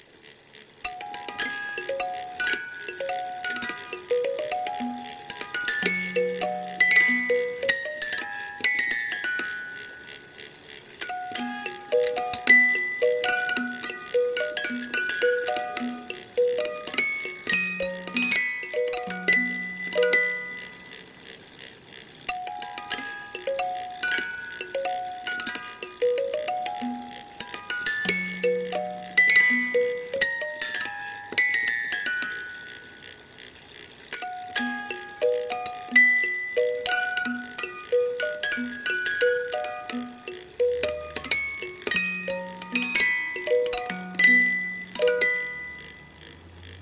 note movement, which seems odd to me.
Please pardon the sound quality; that's as good as
[ Carousel Reuge Movement Playing